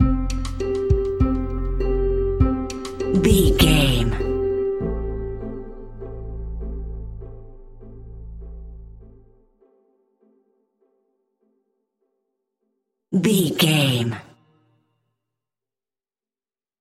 String Suspense Stinger.
Ionian/Major
ominous
haunting
eerie
synthesizer
drum machine
horror music